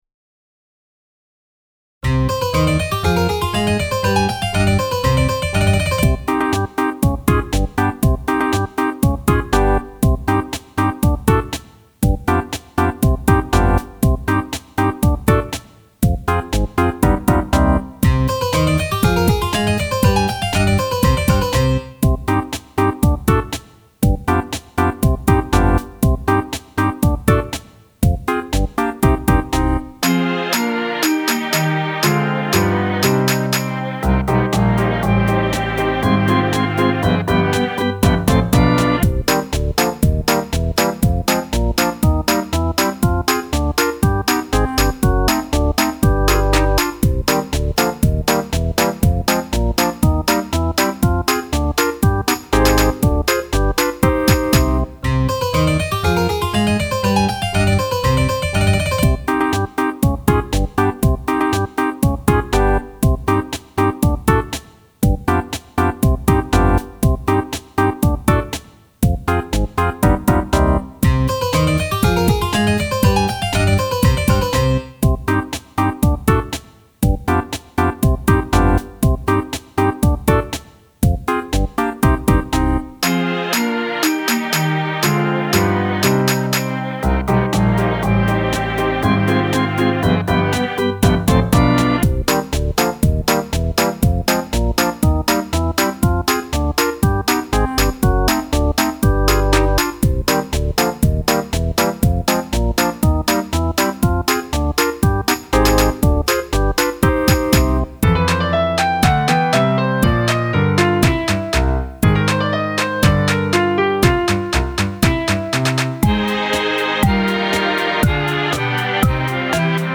【Instrumental / 2010】 mp3 DL ♪